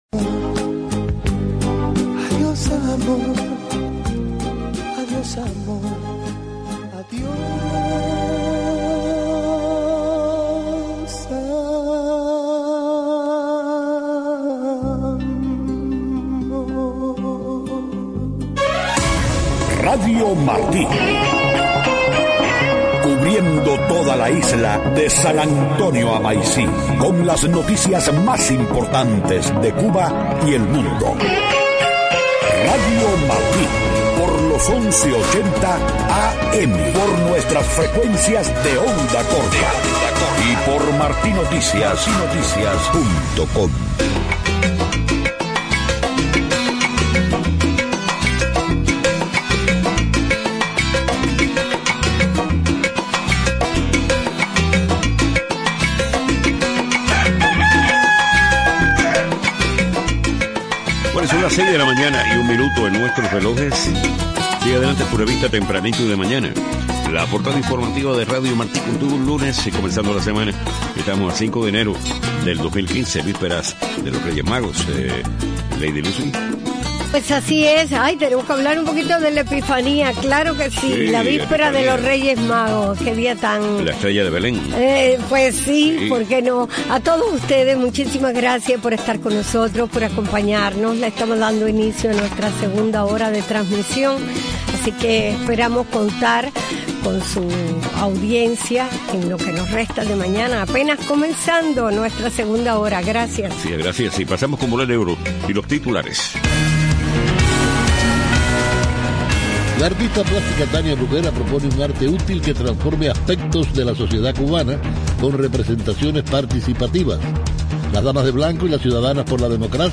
5:00 a.m. Noticias: Artista plástica Tania Bruguera presentará queja ante autoridades judiciales cubanas y organismos internacionales.